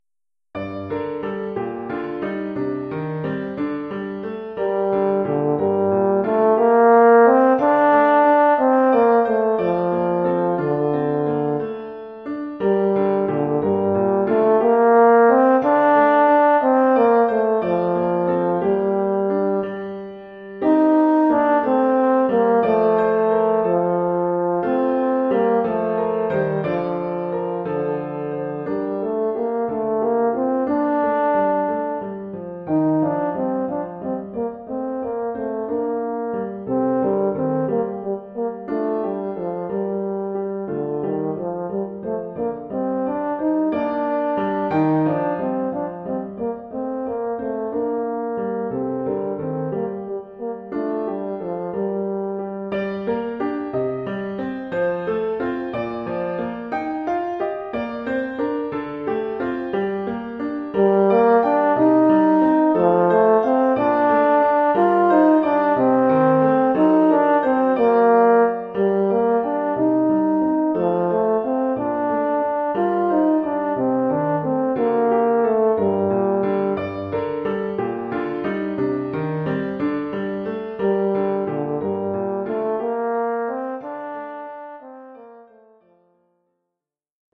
Formule instrumentale : Saxhorn alto et piano
Oeuvre pour saxhorn alto et piano.